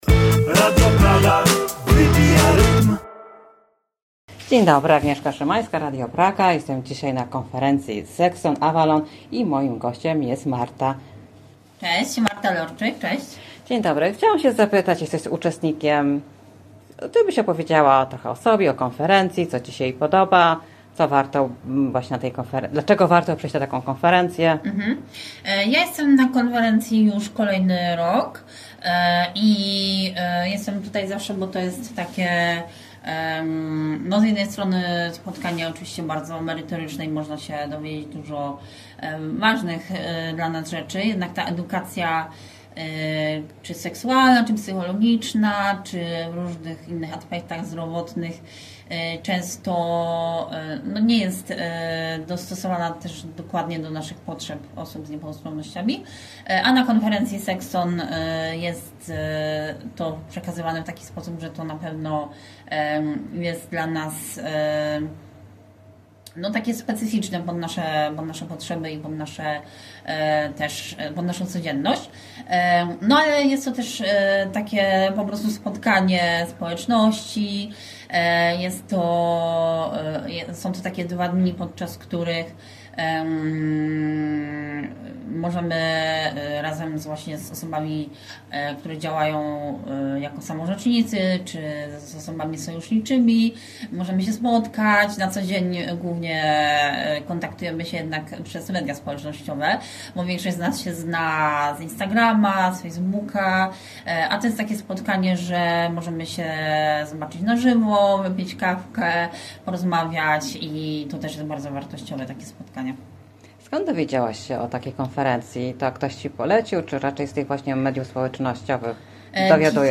O idei samorzecznictwa osób z niepełnosprawnościami oraz o fascynacji podróżami PKP. Zapraszamy na rozmowę o praktyce w codziennym życiu osób niepełnosprawnych.